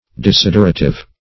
Desiderative \De*sid"er*a*tive\, n.